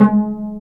Index of /90_sSampleCDs/Roland - String Master Series/STR_Vcs Marc-Piz/STR_Vcs Pz.3 dry